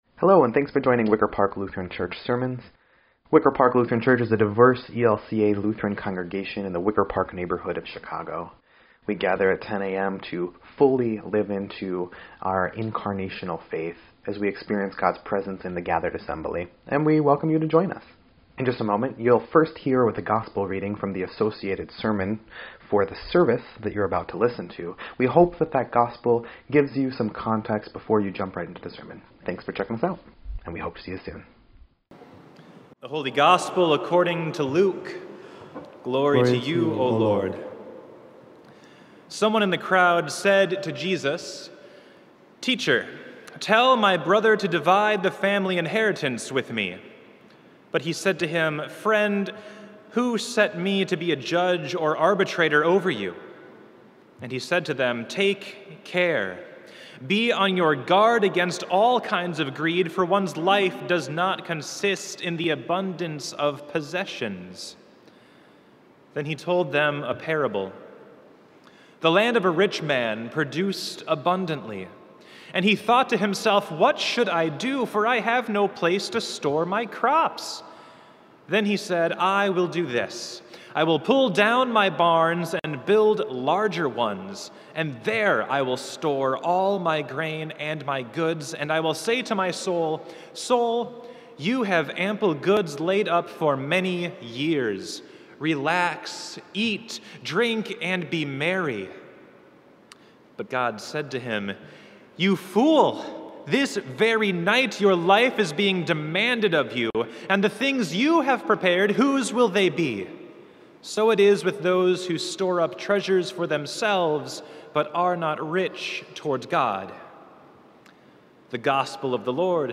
7.31.22-Sermon_EDIT.mp3